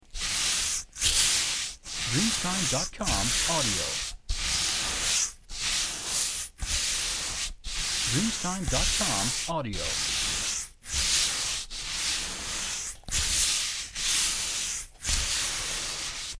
Rallentamento 3
• SFX